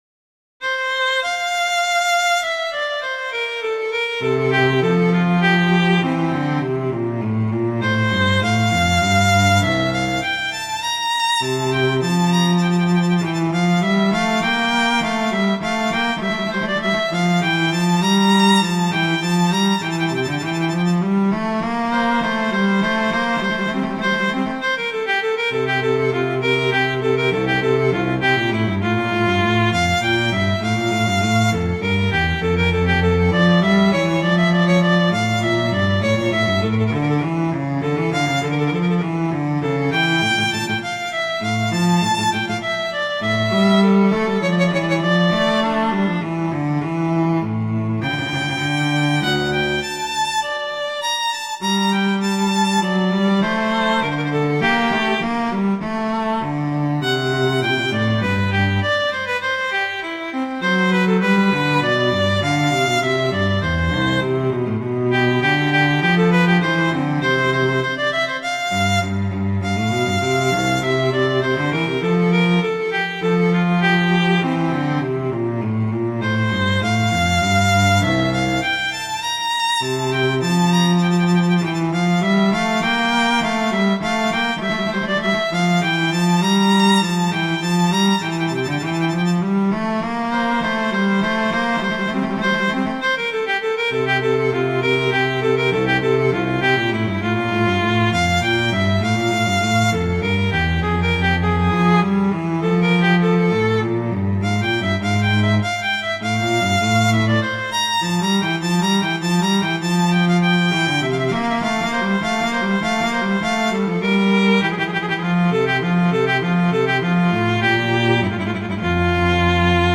transcription for violin and cello
classical